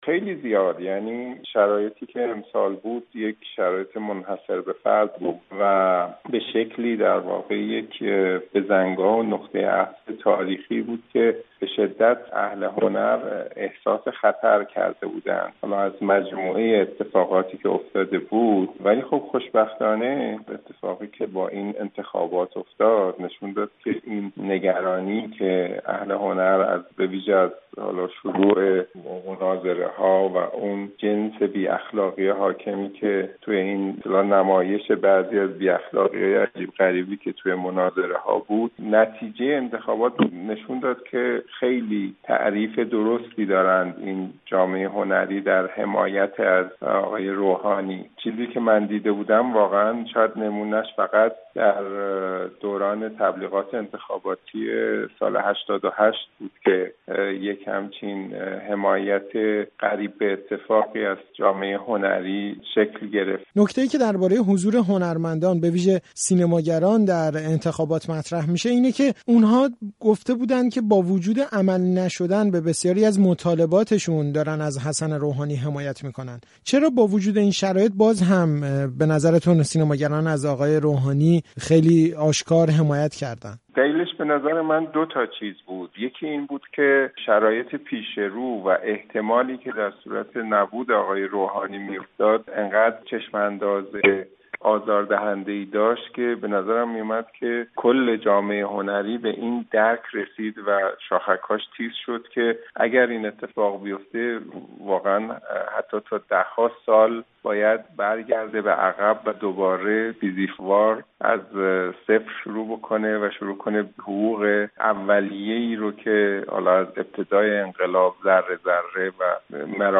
گفتگو با ابوالحسن داودی در مورد حضور و مطالبات هنرمندان در انتخابات